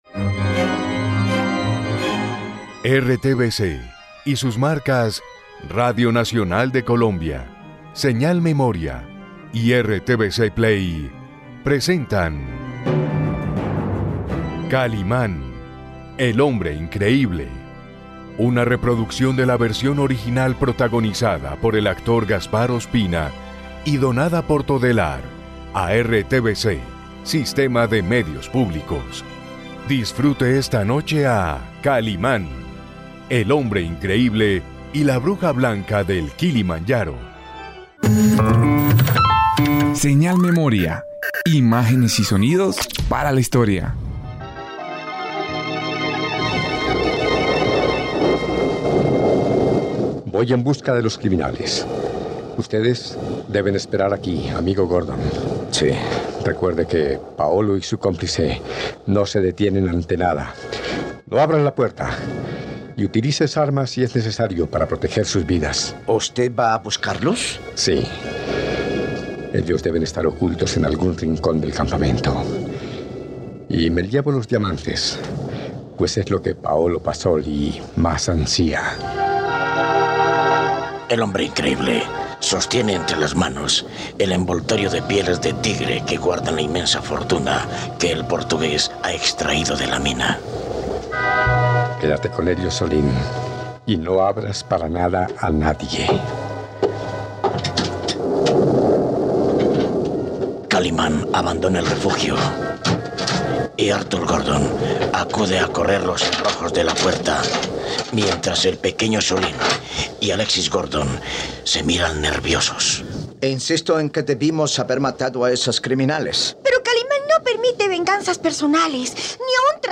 Radionovela Kalimán, el hombre increíble: la bruja blanca del Kilimanjaro ésta en su recta final.